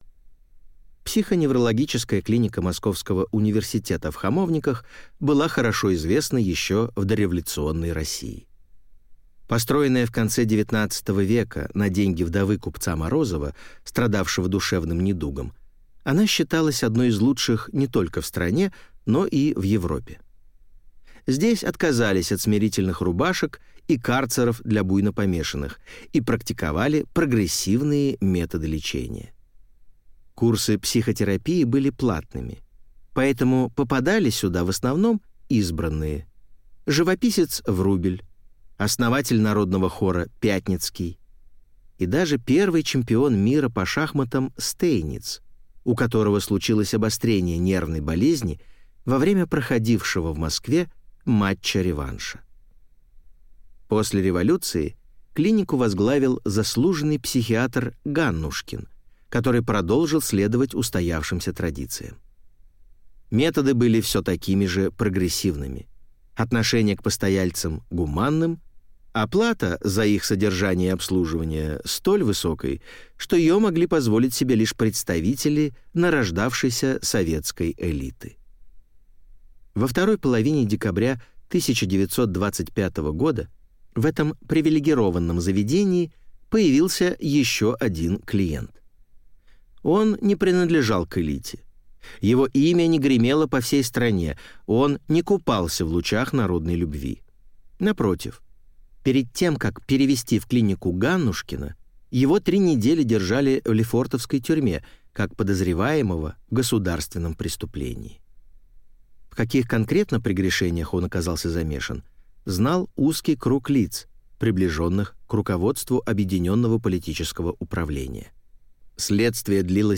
Аудиокнига Авалон | Библиотека аудиокниг
Прослушать и бесплатно скачать фрагмент аудиокниги